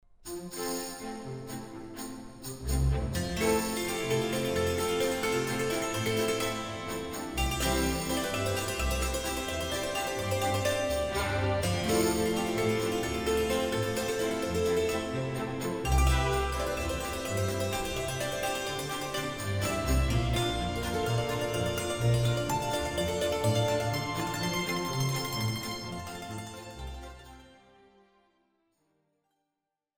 04 - Hackbrett Solo